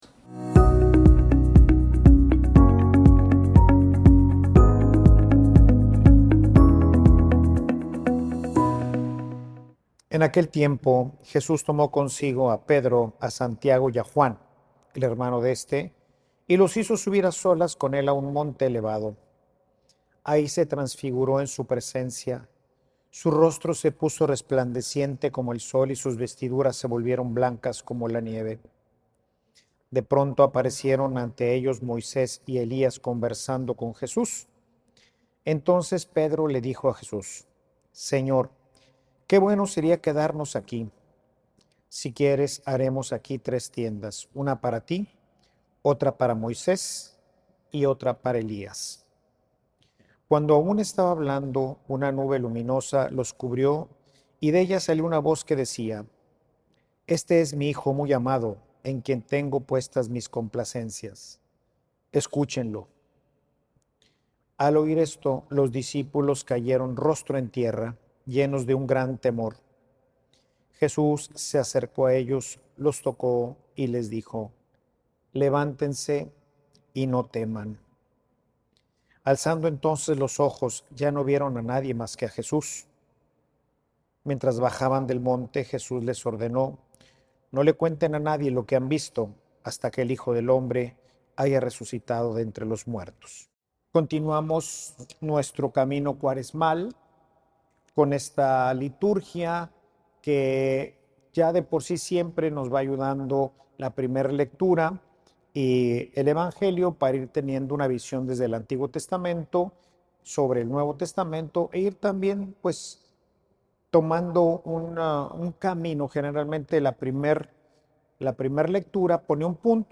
Homilia_La_escucha_implica_obediencia.mp3